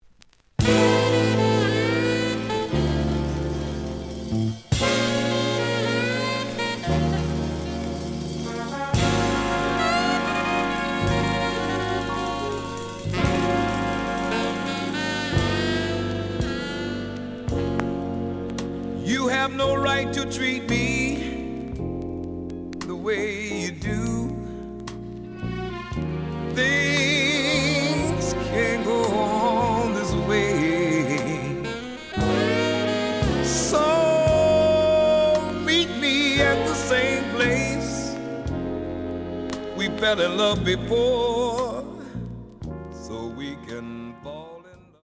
The audio sample is recorded from the actual item.
●Genre: Soul, 70's Soul
Some damage on both side labels. Plays good.)